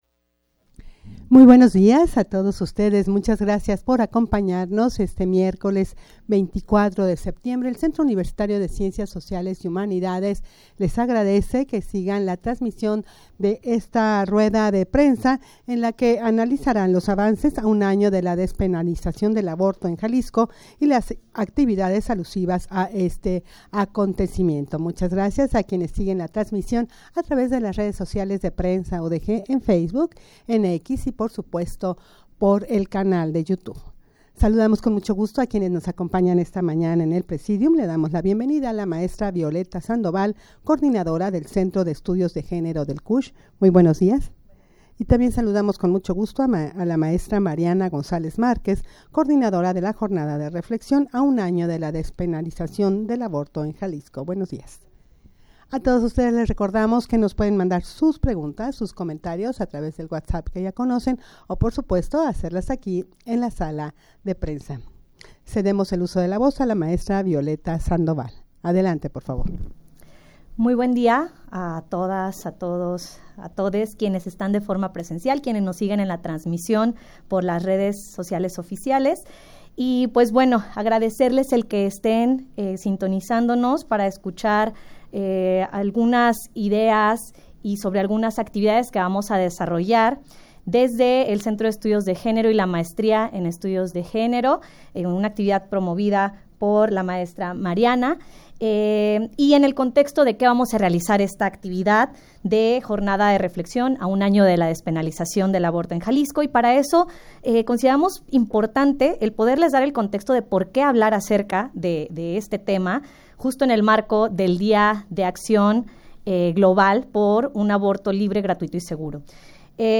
Audio de la Rueda de Prensa
rueda-de-prensa-para-analizar-los-avances-a-un-ano-de-la-despenalizacion-del-aborto-en-jalisco.mp3